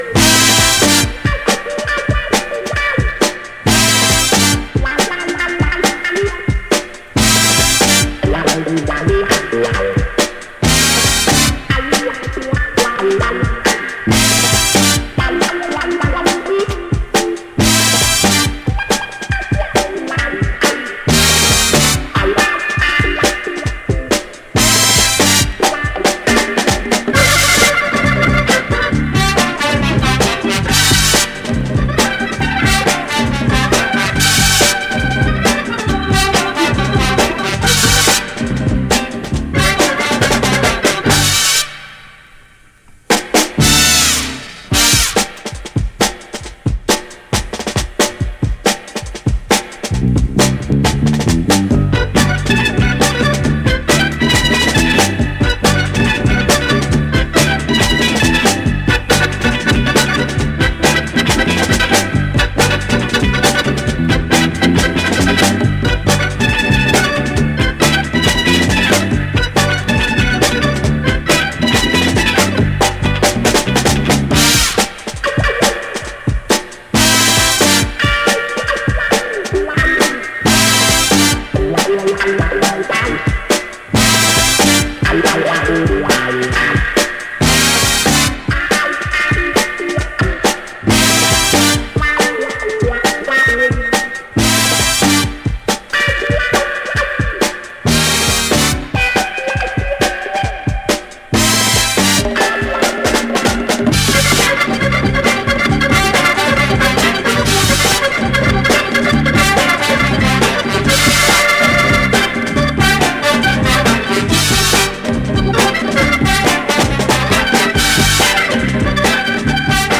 thrilling Latin soul